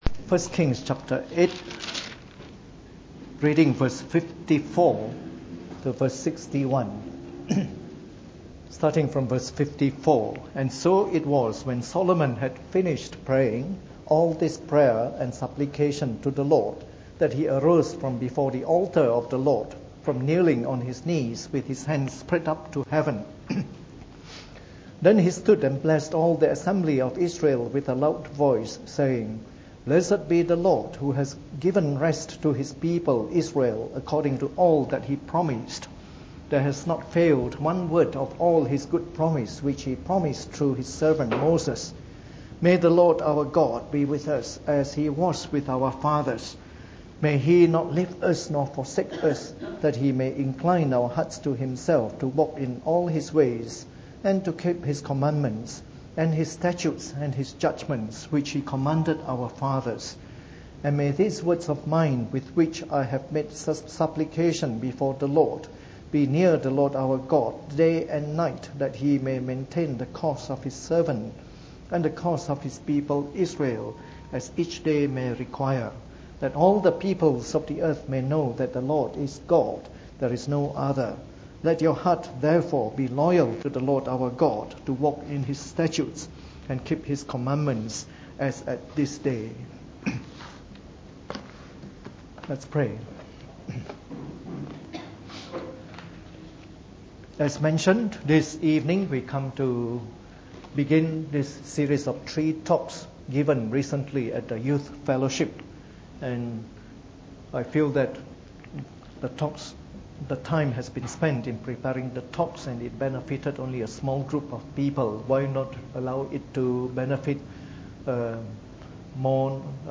Preached on the 22nd of May 2013 during the Bible Study, the first in a short series of talks on “The Life of Solomon.”